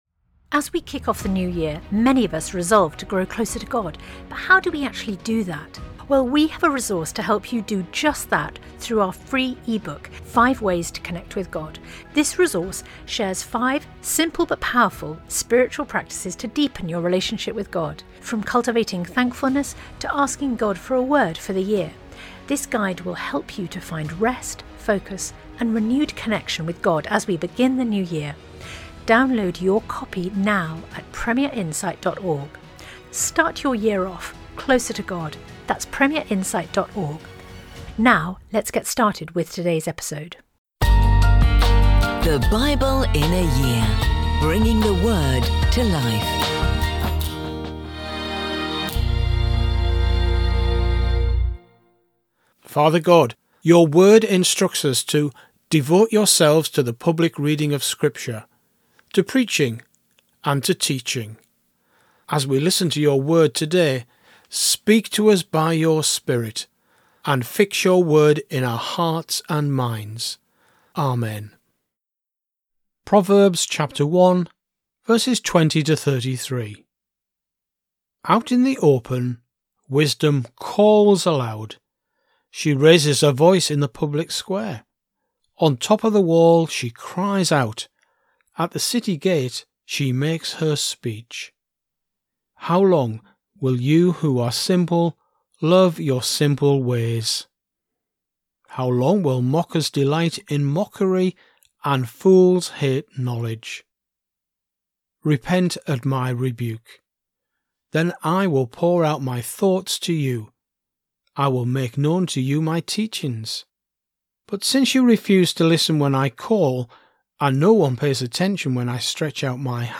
Every day throughout the year we'll be bringing you an audio scripture reading from the Old and New Testament.